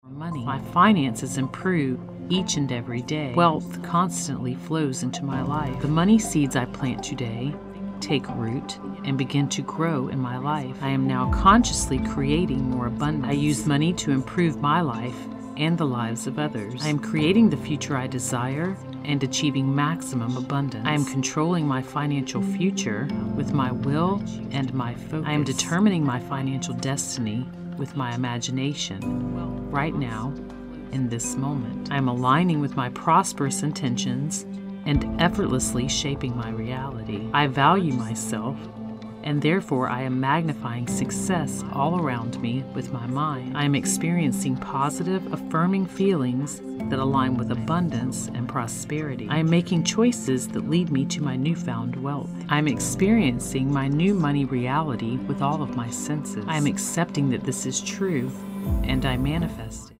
It is combined with a 528 Hz binaural tone, known as the “Miracle Tone” to assist you in manifesting miracles and promoting a calm and confident state.
The affirmations move from ear to ear and back to center to strongly infuse the messages.